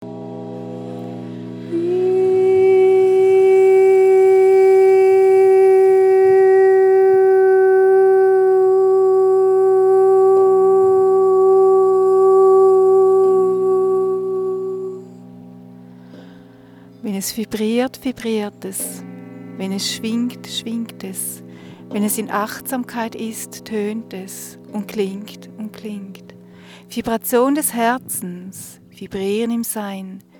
Tauche ein in die faszinierende Welt der Klangskulpturen.
Entdecke hörend das Klangspiel verschiedener Materialien.